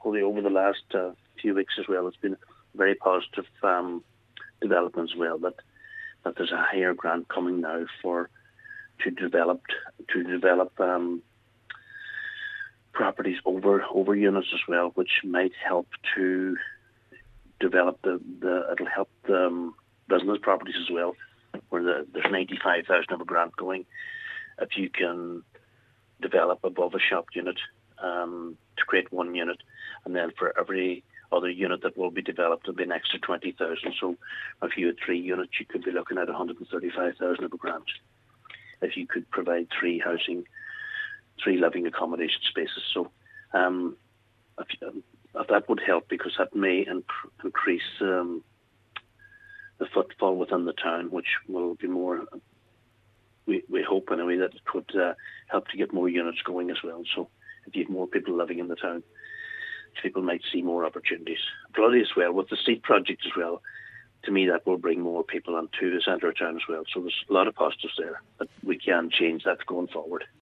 Cllr Harley says plans have been laid down, and he believes that in two to three years there will be a significant improvement: